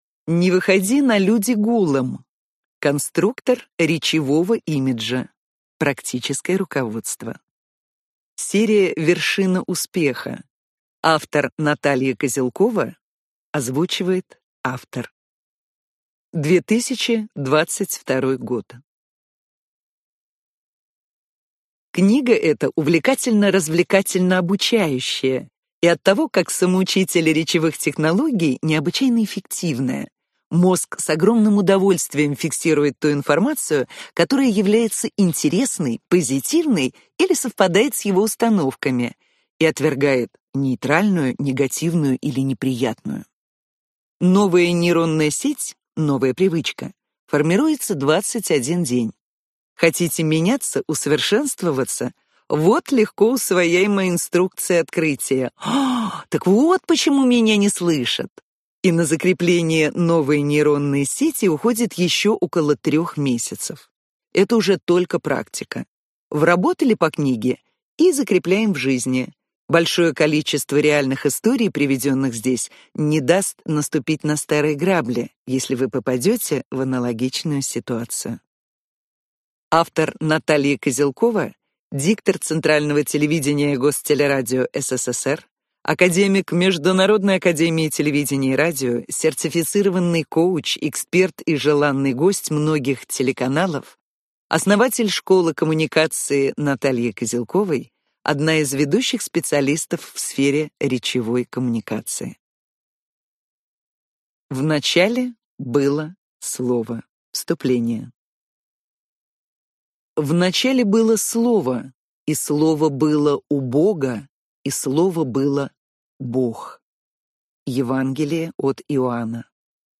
Аудиокнига Не выходи на люди голым! Конструктор речевого имиджа. Практическое руководство | Библиотека аудиокниг